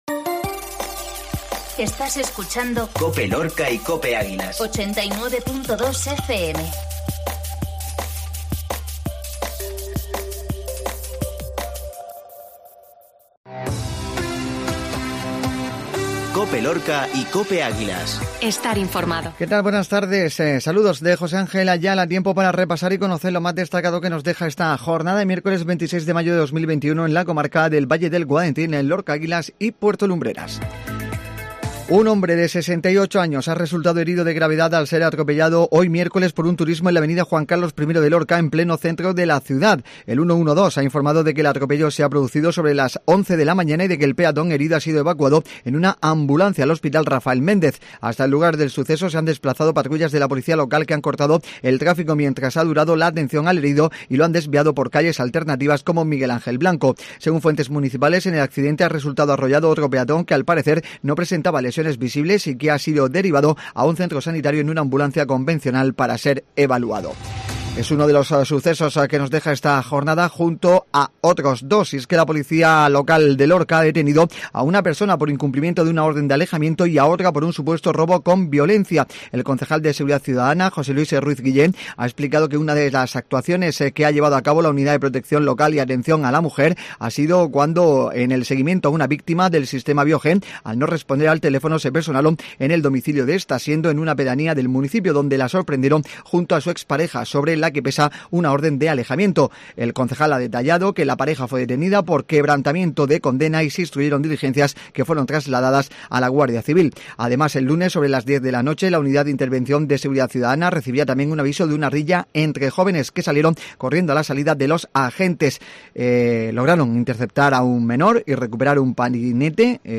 INFORMATIVO MEDIODÍA MIÉRCOLES